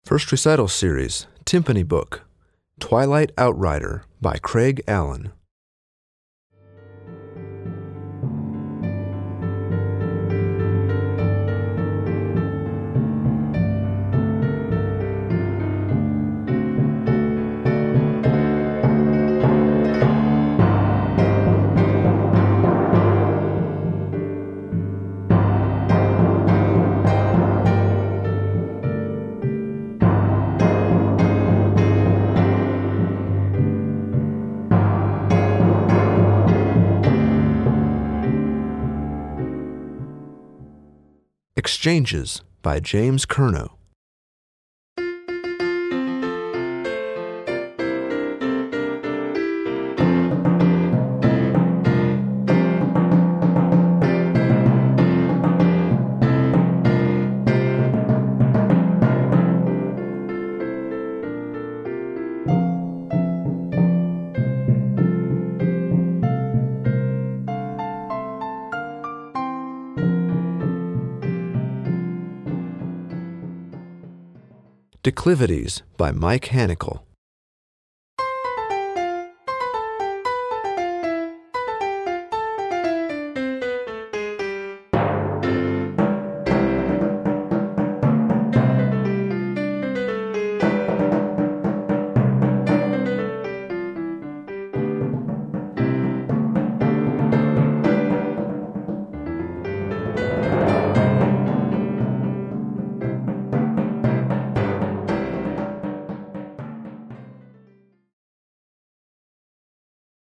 Voicing: Piano Accompaniment